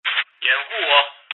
radio_coverme.mp3